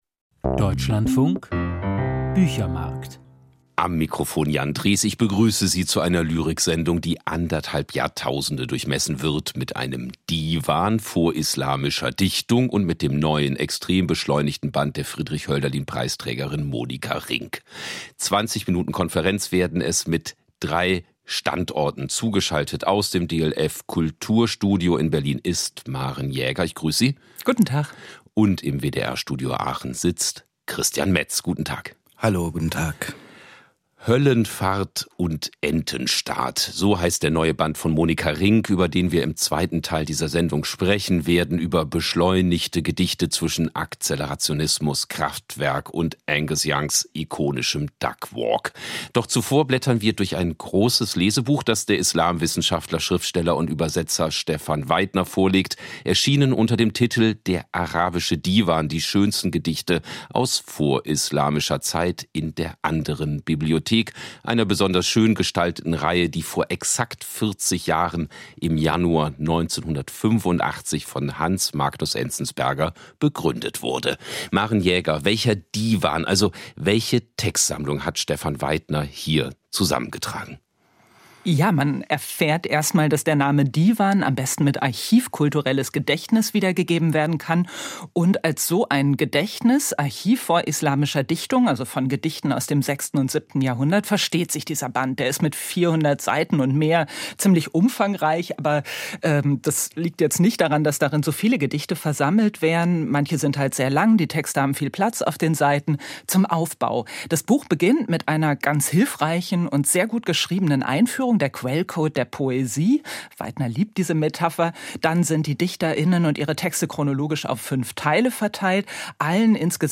Lyrikgespräch
auf der Frankfurter Buchmesse, im Deutschlandfunk „Büchermarkt“ vom 22.